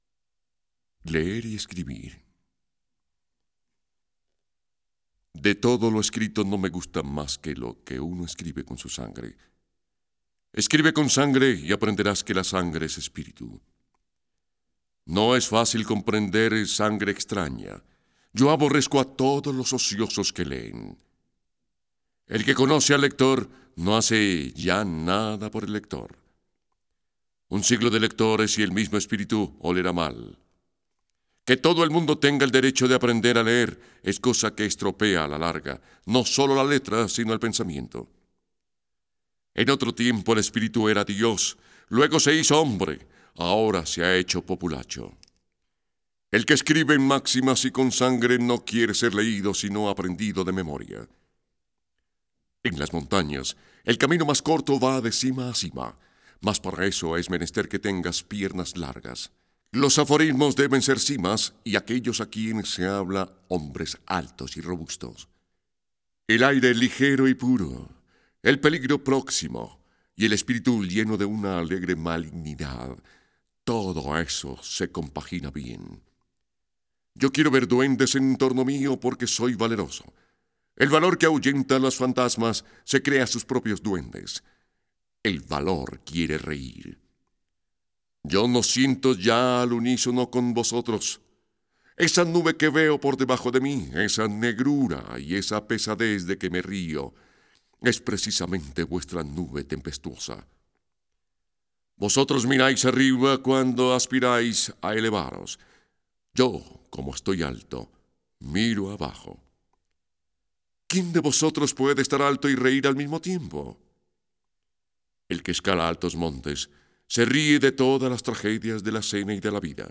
Libros Gnosticos en Audio Mp3